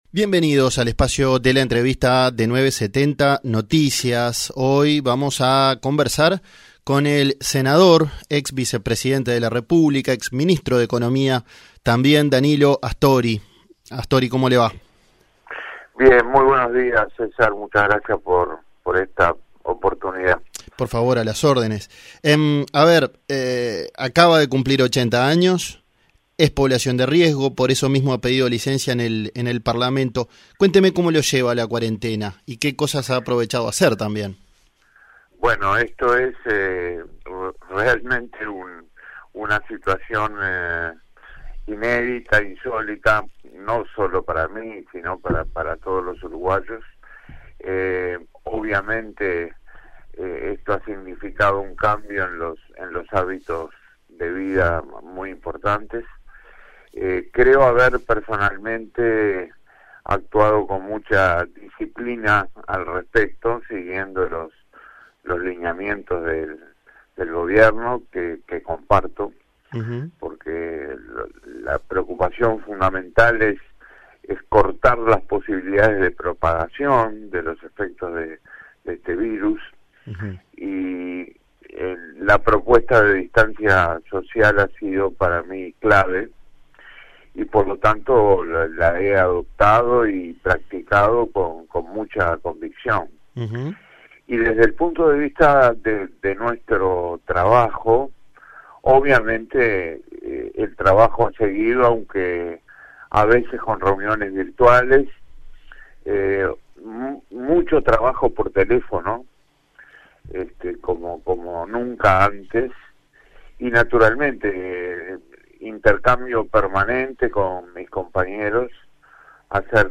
DANILO-ASTORI-entrevista-completa.mp3